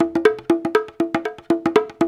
120 -UDU B06.wav